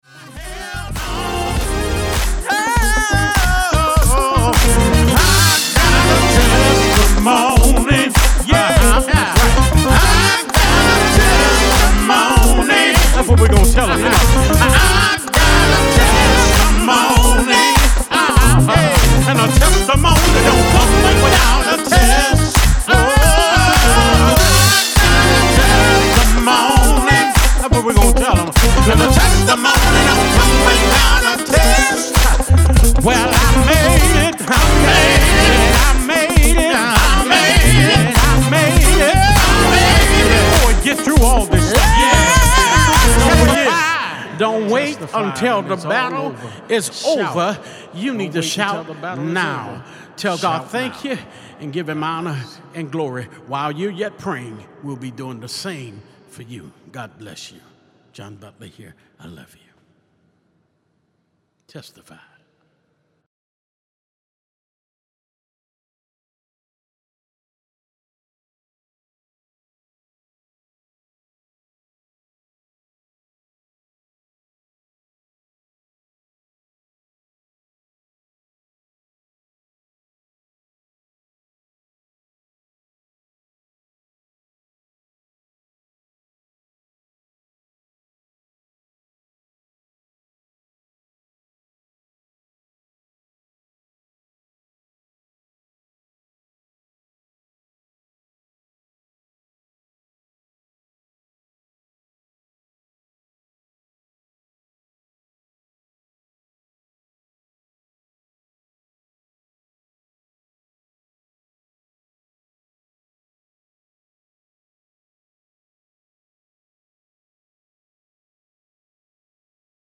RHYTHMIC SOUL